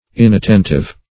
Inattentive \In`at*ten"tive\, a. [Cf. F. inattentif.]